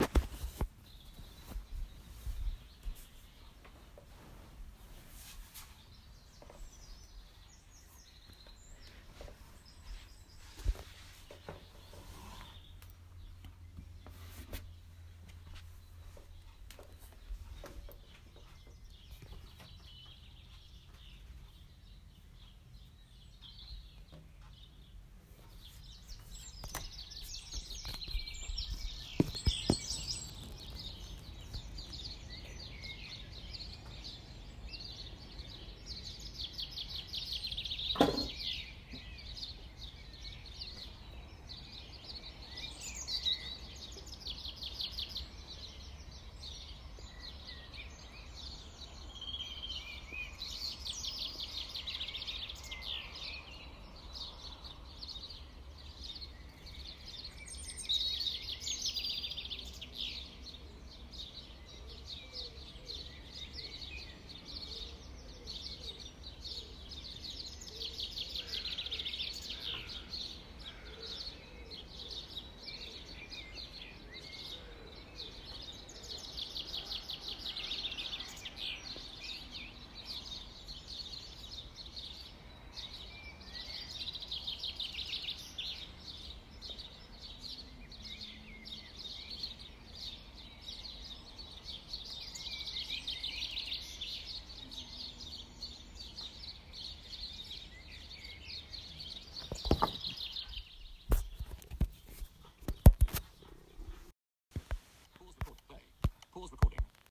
Dawn Chorus from the bedroom window, Thursday 6 April 2017